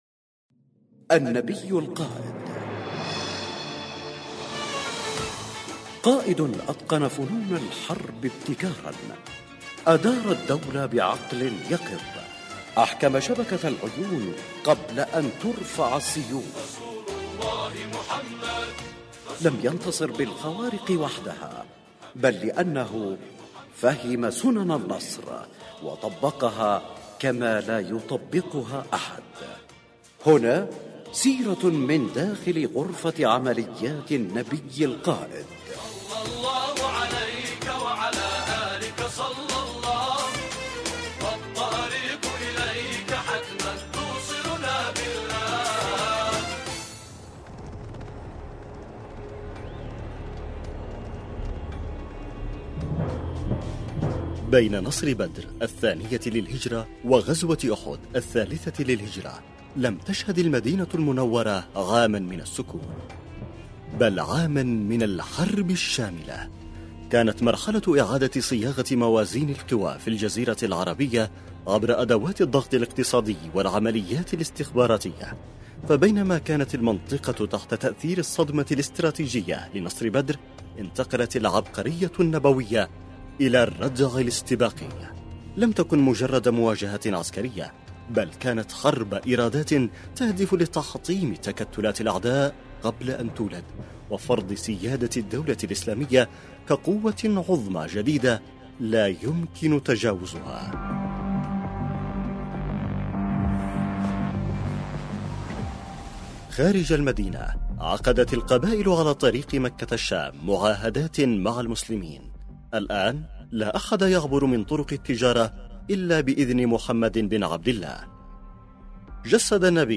النبي القائد، برنامج إذاعي يقدم الجوانب العسكرية والأمنية في السيرة النبوية للنبي الاكرم صلى الله عليه واله مع الاعتماد بشكل كلي على ما ذكره السيد القائد يحفظه الله في محاضراته خلال رمضان وخلال المولد النبوي الشريف.